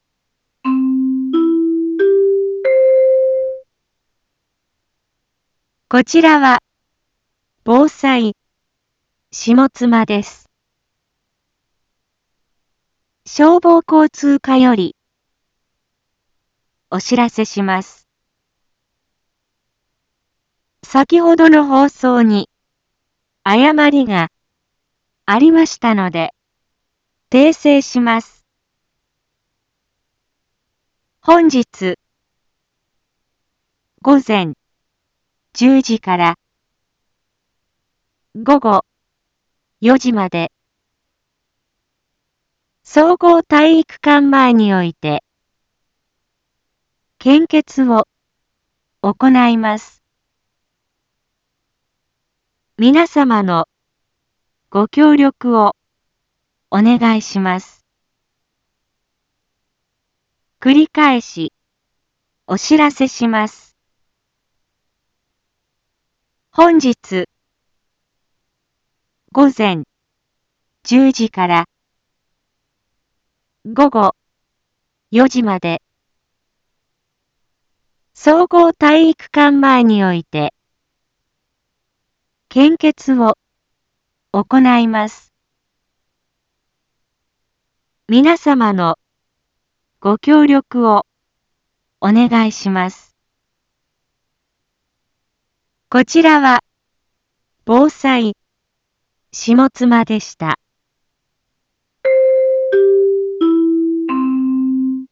一般放送情報
BO-SAI navi Back Home 一般放送情報 音声放送 再生 一般放送情報 登録日時：2022-06-23 10:11:46 タイトル：献血のお知らせ（当日報） インフォメーション：こちらは防災下妻です。